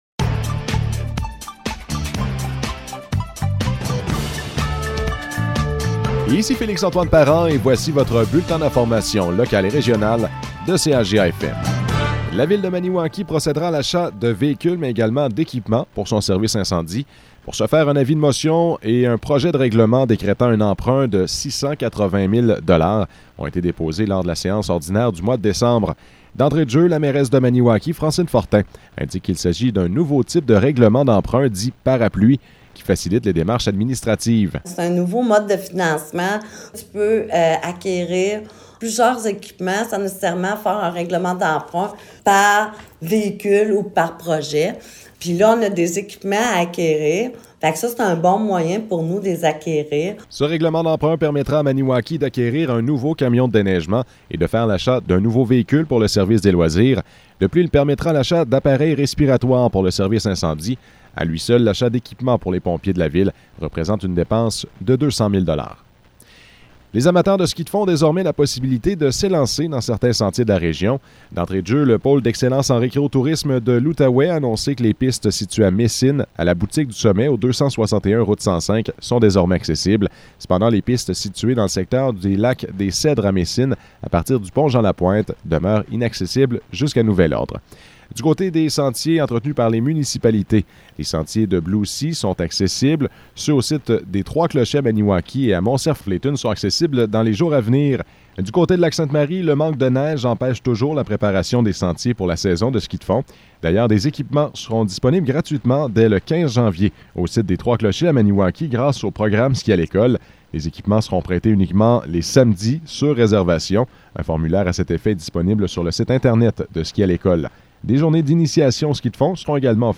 Nouvelles locales - 7 janvier 2022 - 15 h